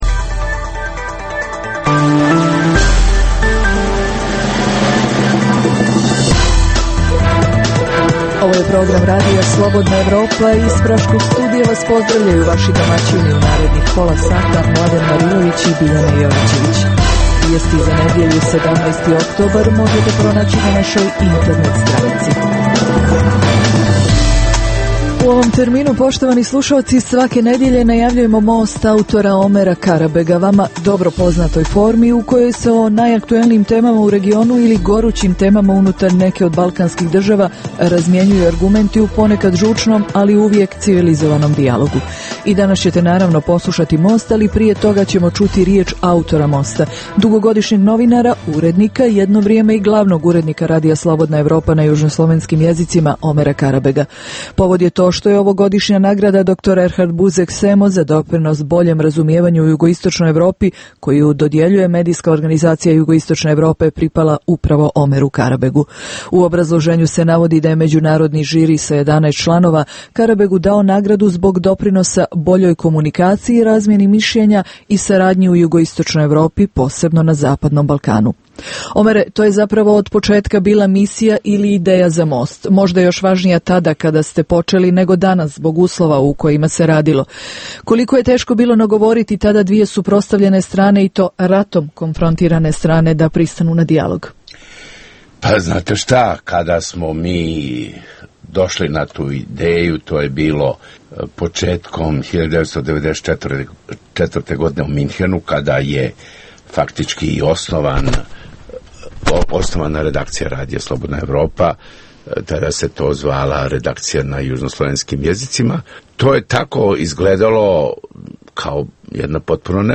u kojem ugledni sagovornici iz regiona diskutuju o aktuelnim temama. Drugi dio emisije čini program "Pred licem pravde" o suđenjima za ratne zločine na prostoru bivše Jugoslavije, koji priređujemo u saradnji sa Institutom za ratno i mirnodopsko izvještavanje iz Londona.